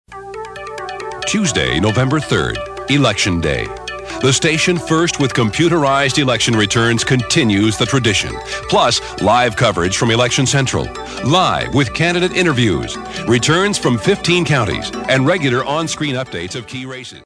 Male Voice Over Talent